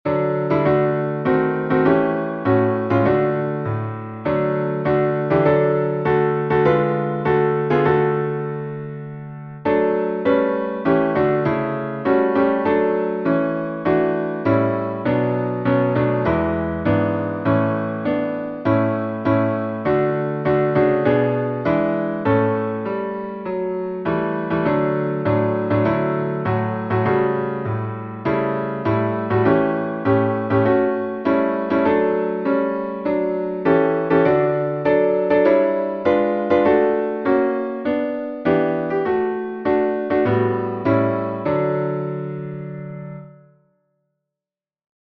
salmo_33B_instrumental.mp3